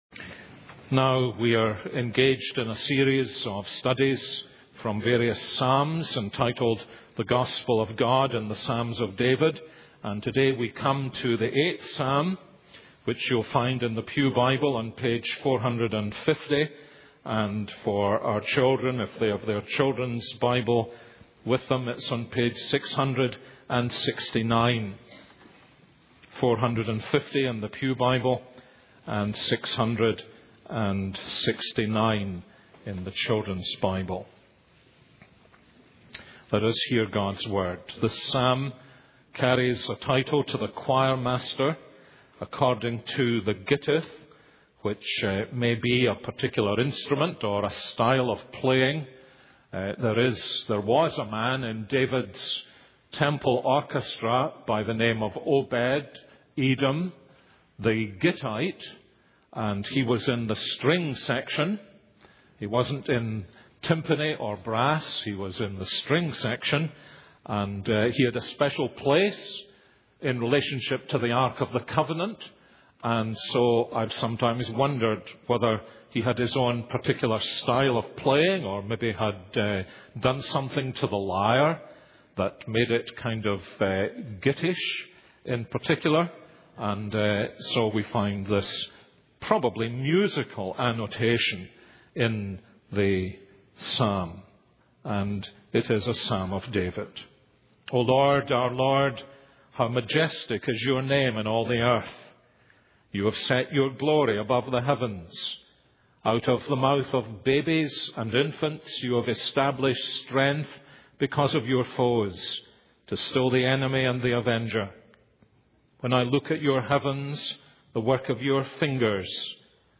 This is a sermon on Psalm 8:1-9.